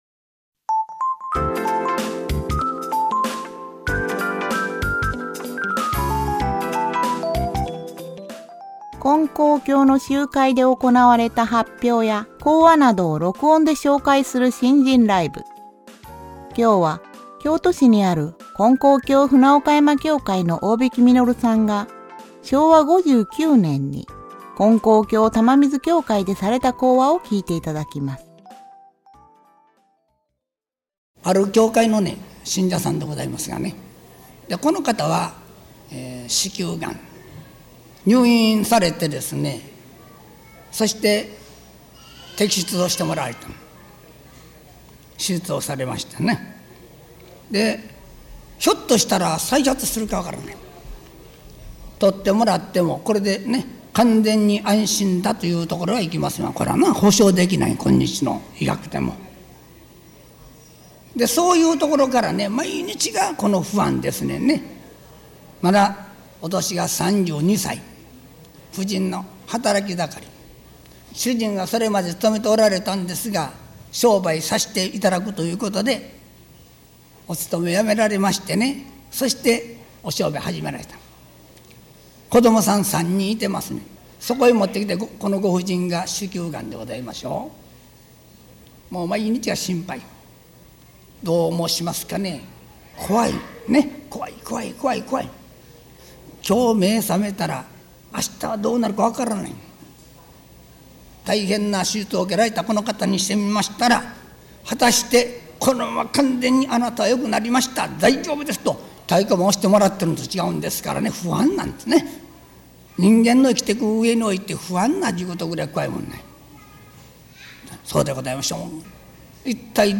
信心ライブ
金光教の集会で行われた発表や講話などを録音で紹介する「信心ライブ」。